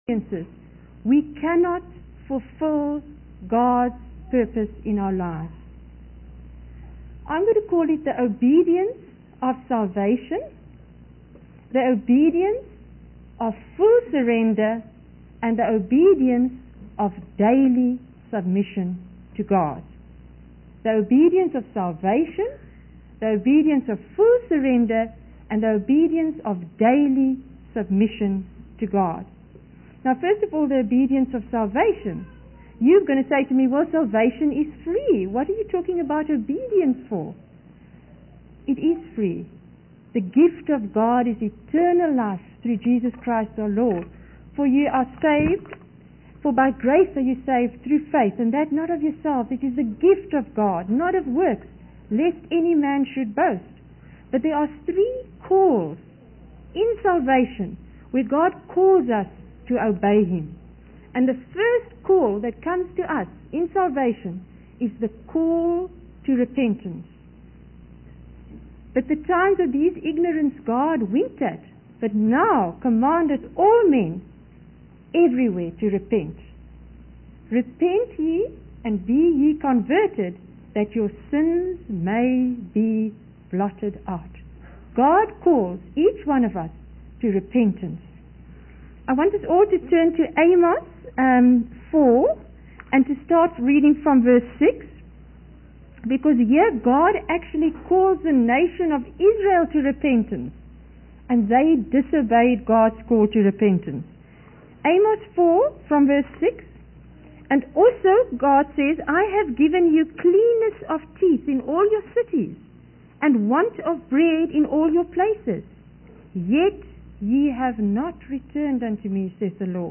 In this sermon, the preacher emphasizes the high price paid on Calvary to conquer and cover all sin.